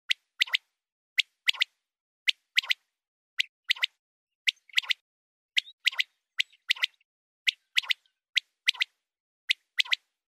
Звуки перепела